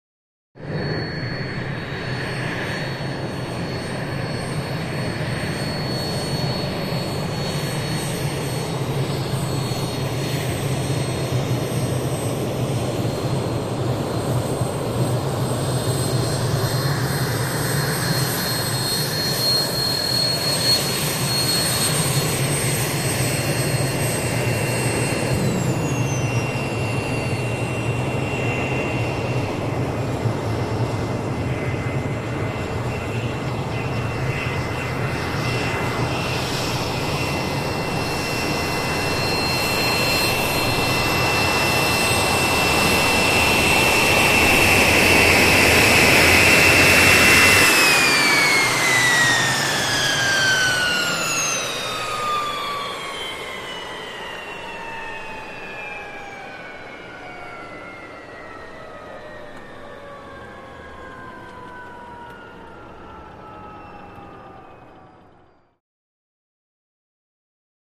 Lear: Series Of 2: In / Off; Approach With Low Drone And Piercing Whistle, Landings With Ignitions Of Landing Jets. Powering Down With Descending Whistles And Whines, Slowing Rhythmic Engine Clicks. Medium To Close Perspective. Jet.